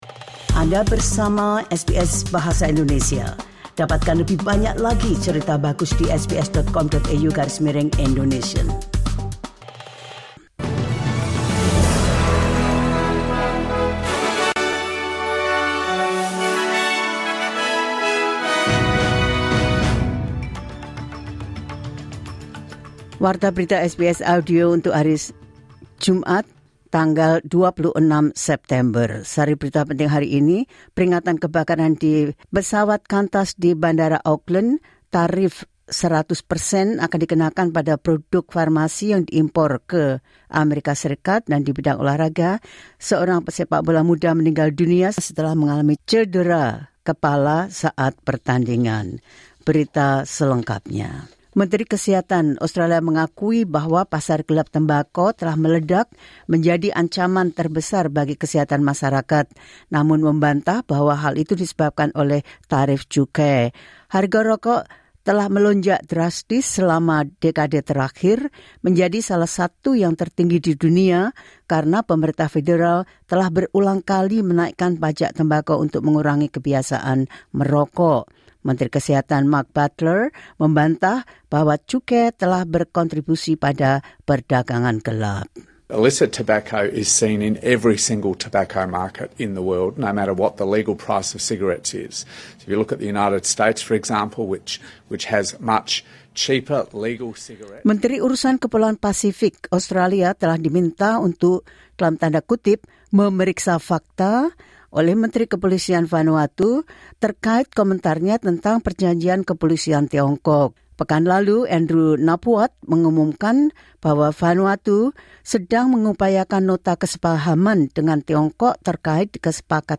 The latest news SBS Audio Indonesian Program – 25 September 2025.